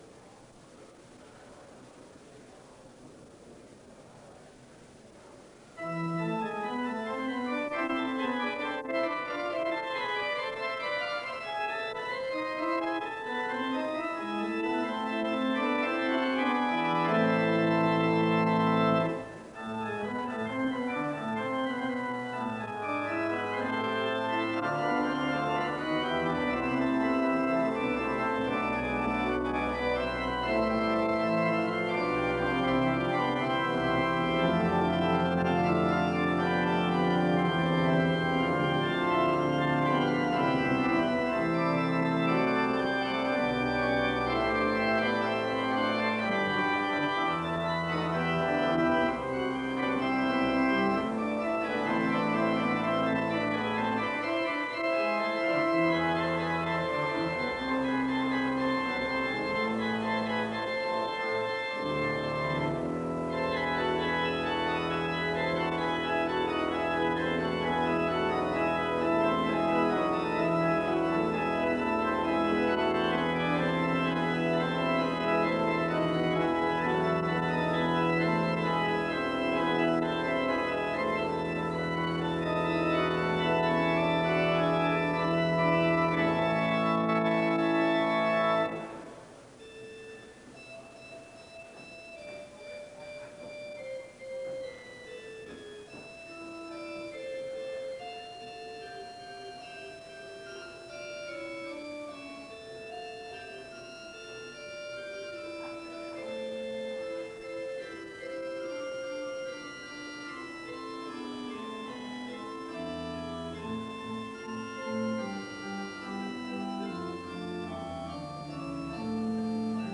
In Collection: SEBTS Chapel and Special Event Recordings SEBTS Chapel and Special Event Recordings - 1980s Thumbnail Titolo Data caricata Visibilità Azioni SEBTS_Adams_Lectures_William_Willimon_1983-02-10.wav 2026-02-12 Scaricare